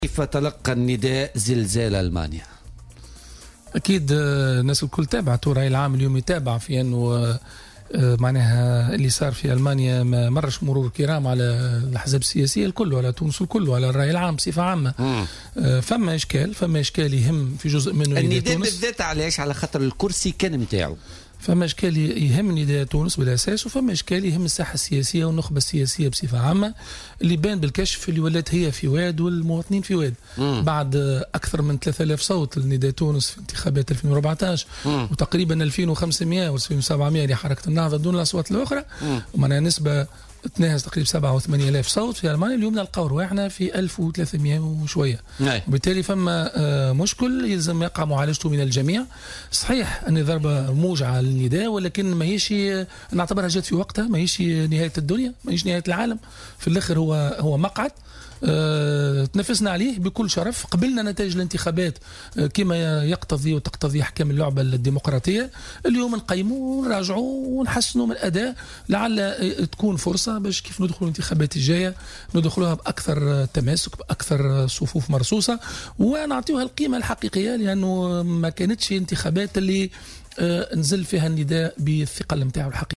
وأوضح في مداخلة له اليوم في برنامج "بوليتيكا" أنه يجب العمل اليوم على تحسين الأداء استعدادا للاستحقاقات الانتخابية القادمة.